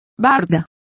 Complete with pronunciation of the translation of bard.